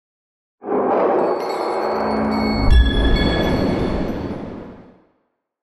abjuration-magic-sign-circle-intro.ogg